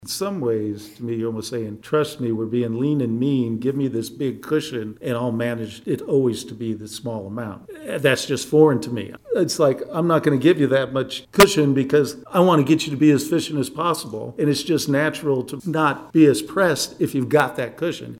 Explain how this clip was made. Discussions regarding the overall 2024 budget took place Tuesday during the Manhattan City Commission’s work session.